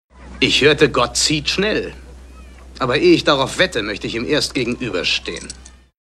Dennoch wurde Chevaliers markante Stimme den meisten Kinogängern vertraut: Von 1951 bis Anfang der 2000er-Jahre war Chevalier umfangreich im Synchrongeschäft tätig.
H Ö R B E I S P I E L E – in der finalen Tonmischung:
Stimmprobe: – Kris Kristofferson (als William H. Bonney (Billy the Kid)) in Pat Garrett jagt Billy the Kid (1973)